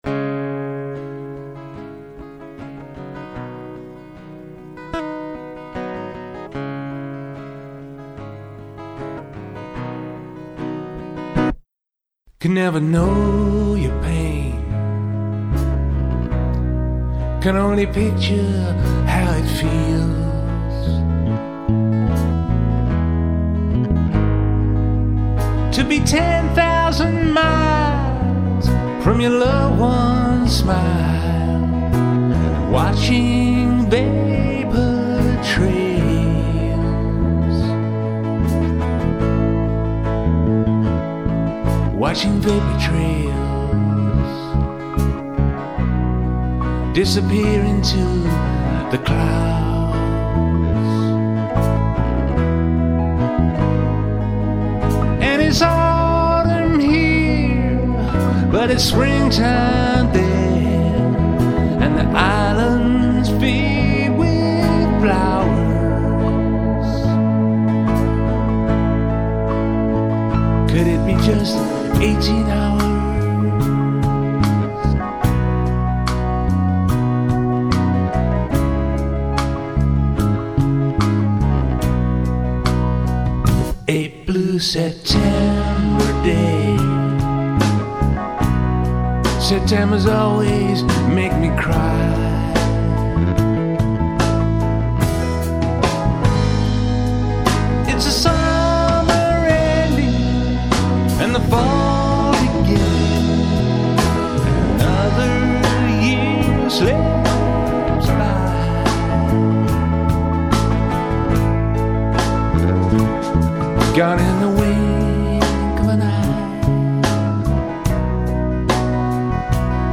Saxophone, flute, percussion